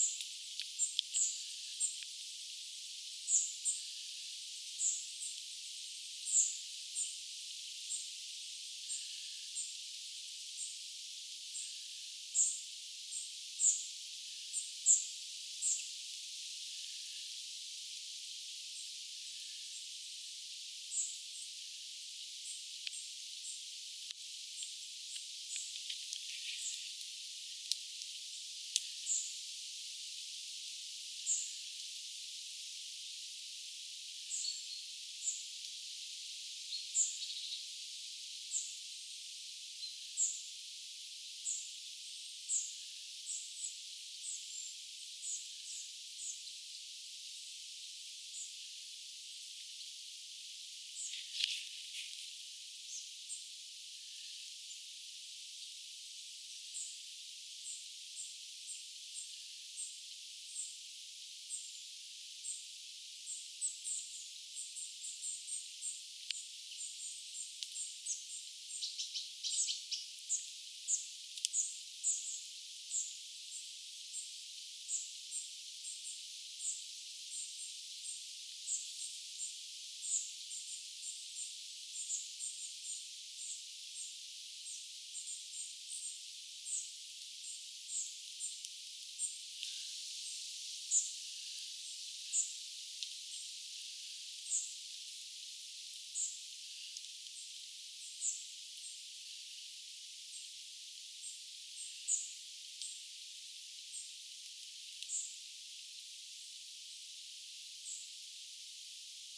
nuorten harmaasieppojen ääntelyä?
harmaasieppojen_ehka_nuorten_aantelya.mp3